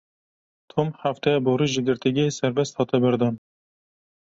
Pronounced as (IPA)
/sɛɾˈbɛst/